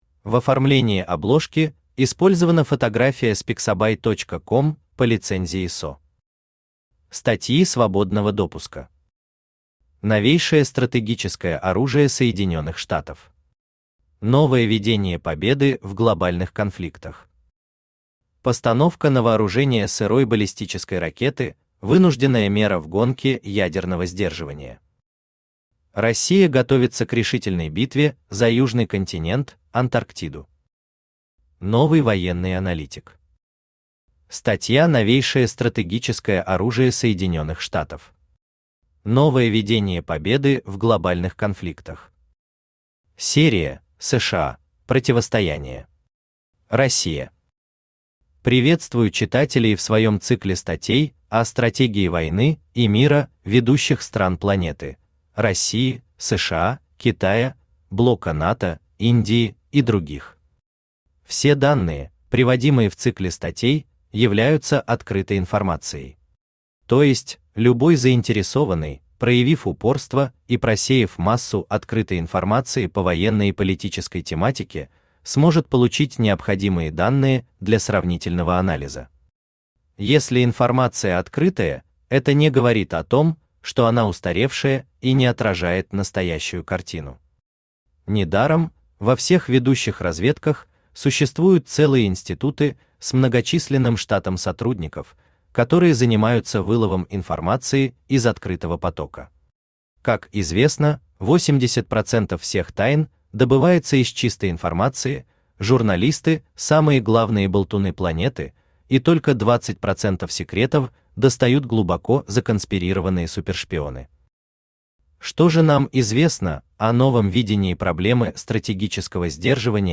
Аудиокнига Раздел Антарктиды: готовы ли мы?
Автор Алексей Николаевич Наст Читает аудиокнигу Авточтец ЛитРес.